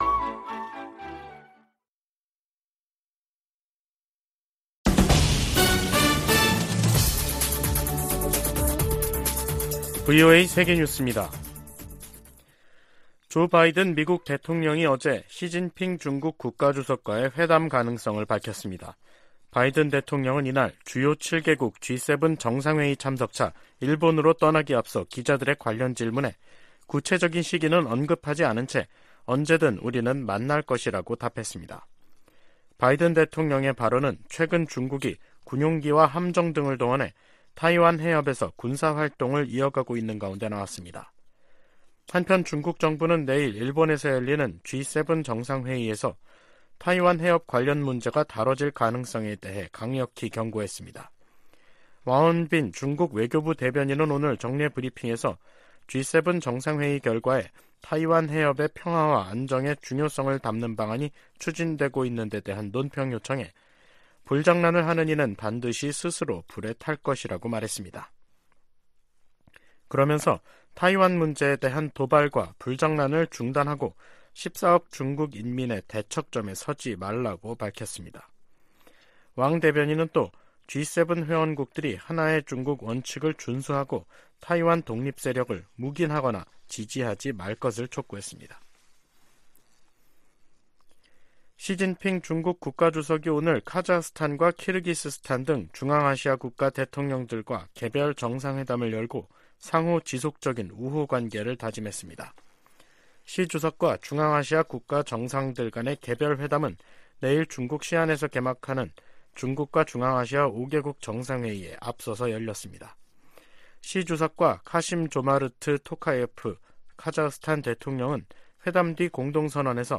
VOA 한국어 간판 뉴스 프로그램 '뉴스 투데이', 2023년 5월 18일 2부 방송입니다. 일본 히로시마에서 열리는 주요 7개국(G7) 정상회의 기간에 미한일 정상회담을 추진 중이라고 미국 백악관 고위 당국자가 밝혔습니다. 미 국무부 북한인권특사 지명자는 유엔 안보리의 북한 인권 공개회의를 재개하고 인권 유린에 대한 책임을 묻겠다고 밝혔습니다. 윤석열 한국 대통령이 주요국 정상들과 잇따라 회담을 갖는 '외교 슈퍼위크'가 시작됐습니다.